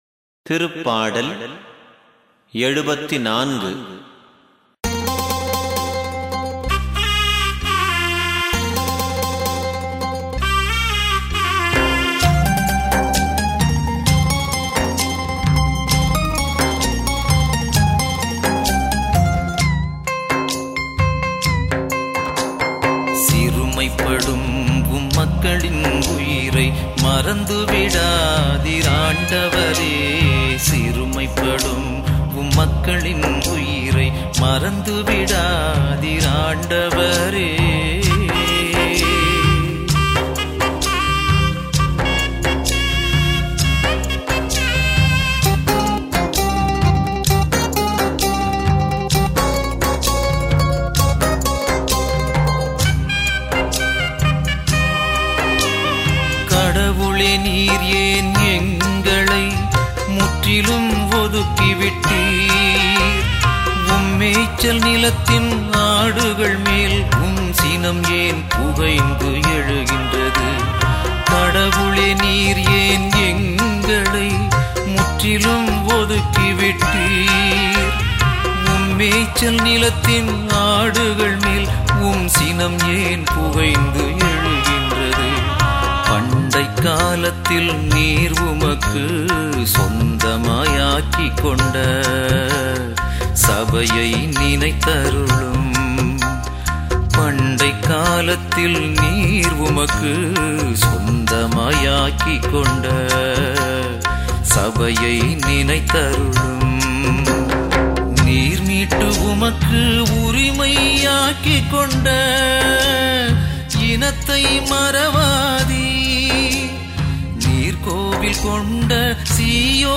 பதிலுரைப் பாடல் -